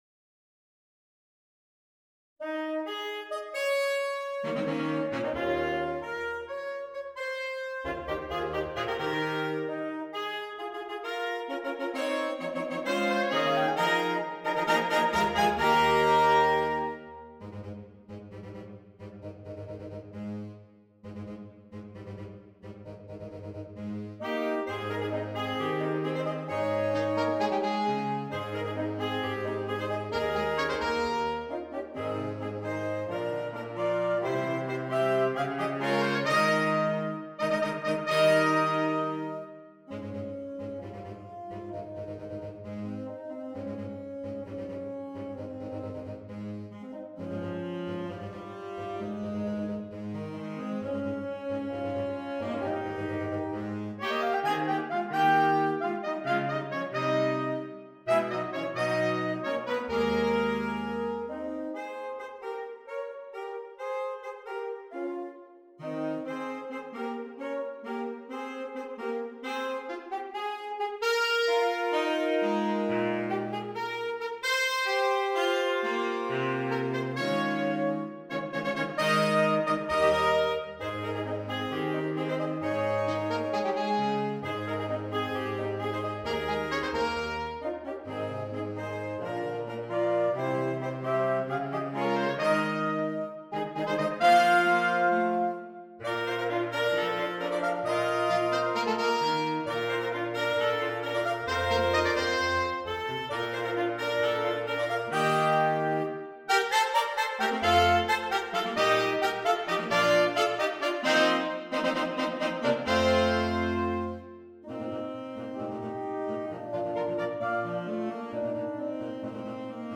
Voicing: Saxophone Sextet (SAATTB)